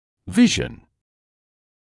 [‘vɪʒn][‘вижн]видение, представление; зрение